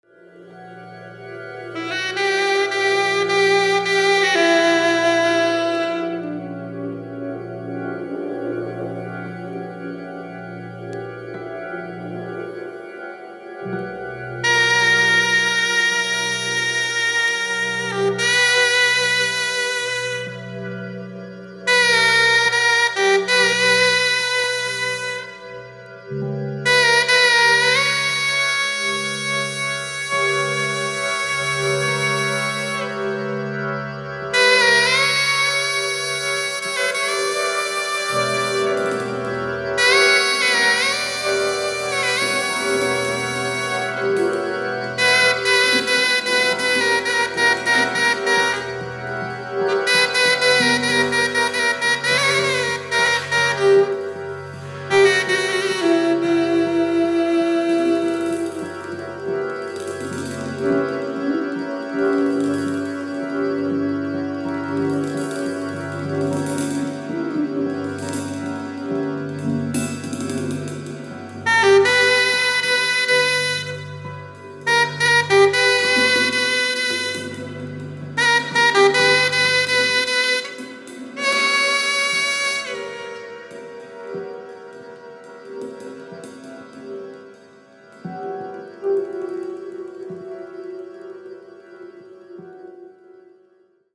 Drums
Organ, Piano
Guitar
Saxophone